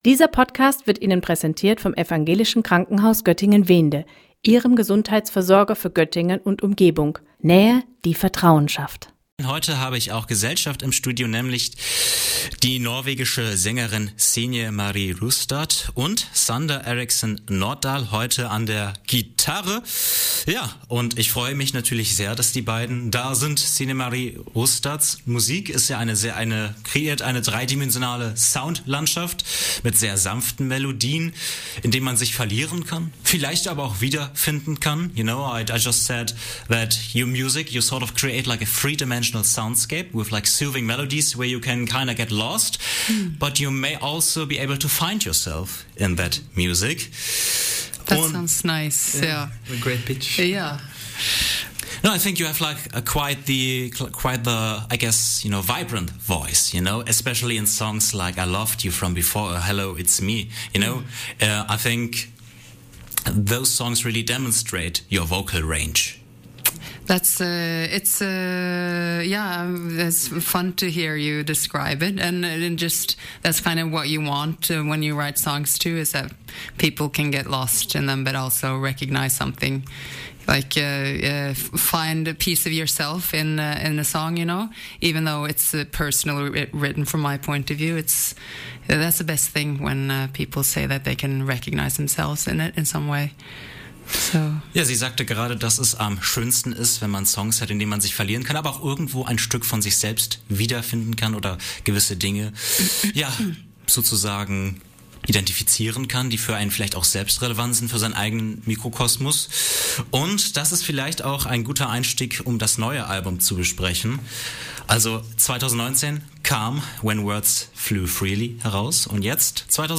Dreidimensionale Klanglandschaften aus Norwegen